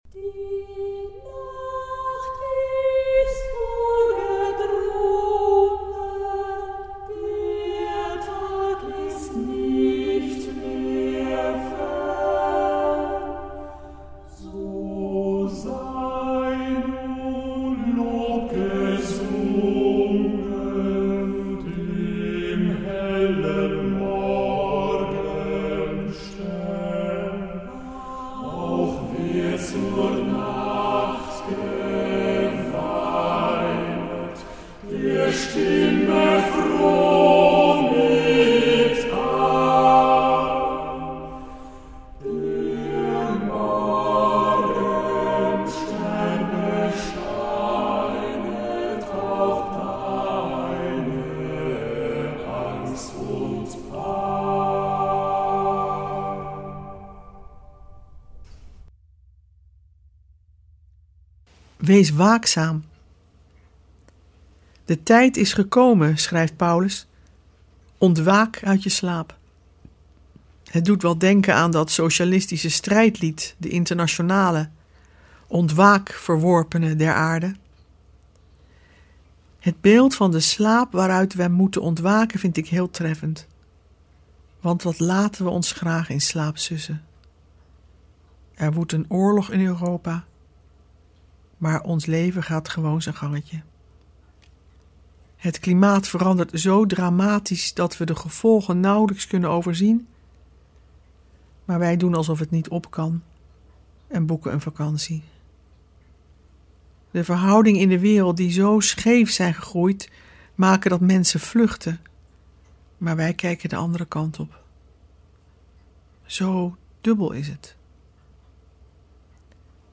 We verbinden ze met onze tijd en we luisteren naar prachtige muziek en poëzie.